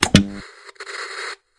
tv_on.mp3